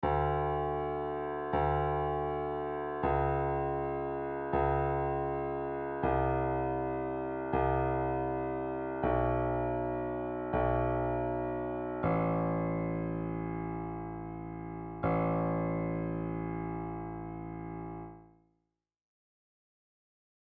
Dark Scary Melodies
When you play a few notes LOW on the piano, they can sound quite dark.
Piano Notes
Play these notes as LOW as you can on the piano
thriller3.mp3